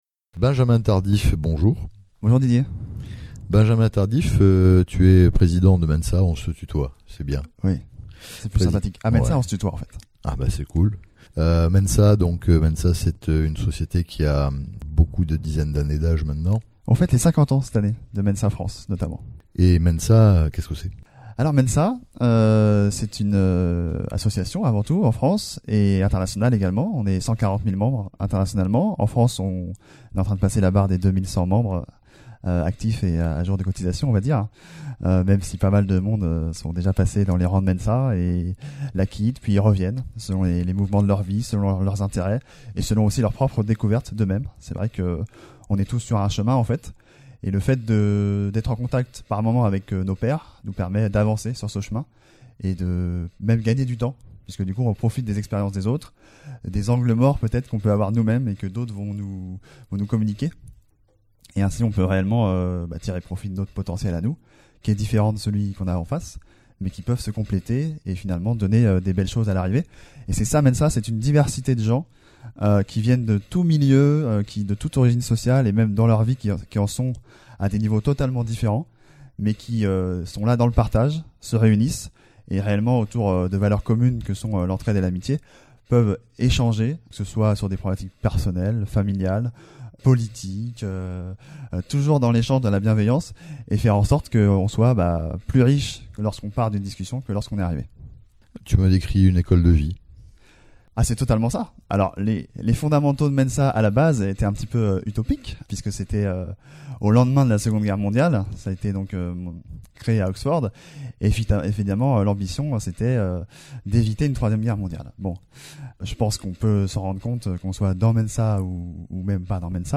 Interviews de spécialistes du Haut Potentiel